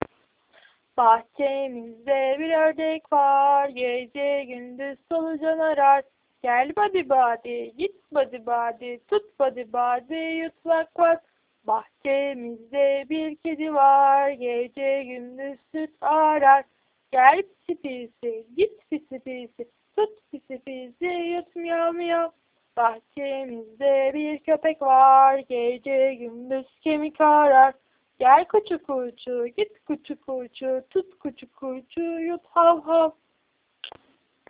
ŞARKILARIN MÜZİKLERİ(KENDİ SESİMDEN)